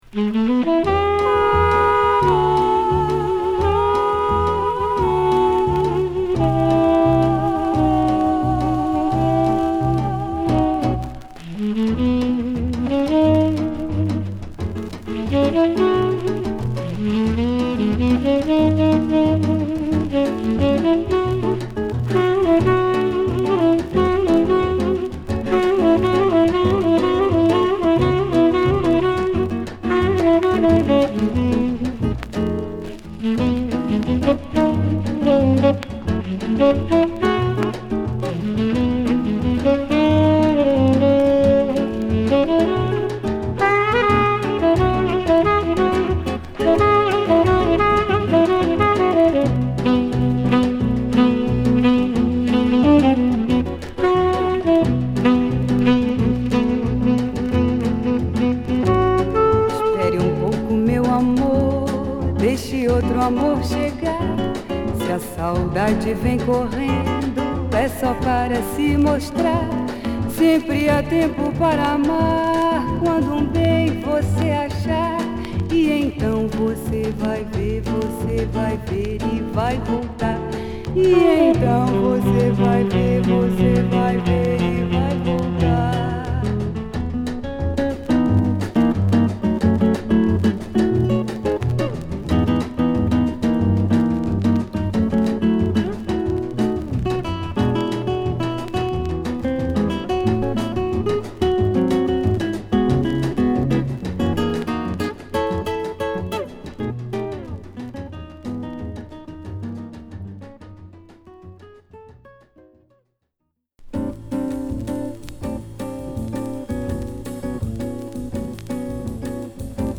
＊盤面曇りでチリノイズが薄めですが出ます。